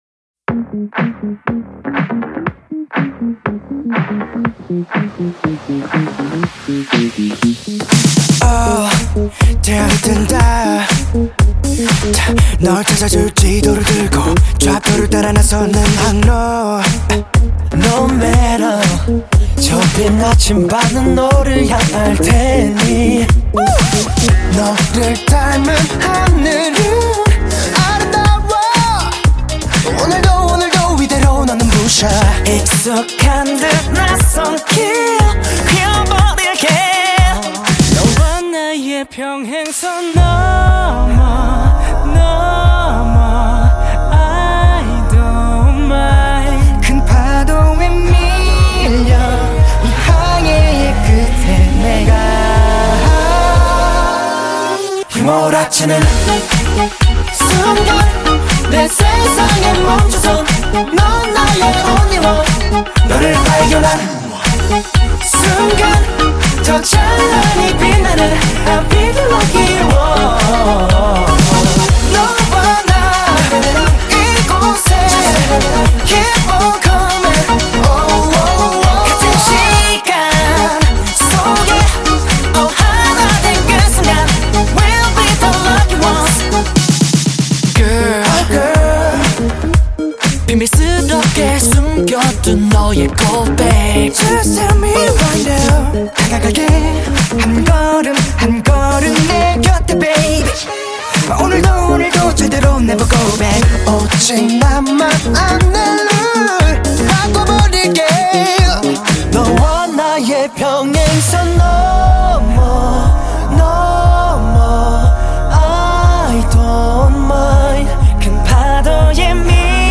سبک: پاپ دنس و آر اند بی